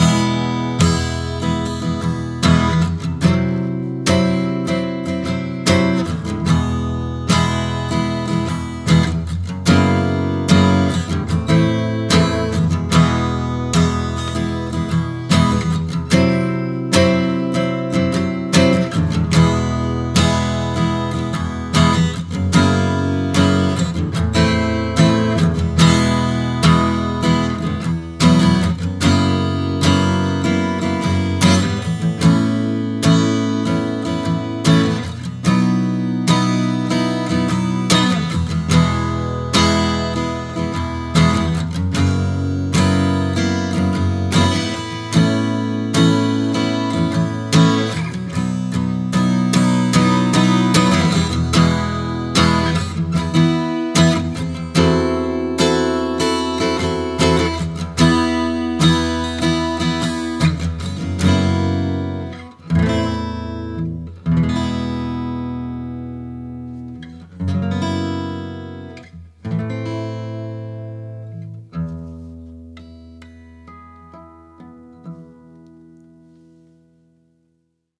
Here is a test recording I did with a Neumann U87 and a friends acoustic guitar.
It has a very tight and controlled sound I think.  Strings are medium gauge phosphor-broze.
There is also a slight amount of compression (very little...this track still has over 15dB of dynamic range!) and a bit of room sized reverb.  Mike is about 2 feet away right at about the 12th fret, pointed at the space between the sound hole and the beginning of the neck.
u87_guitar.mp3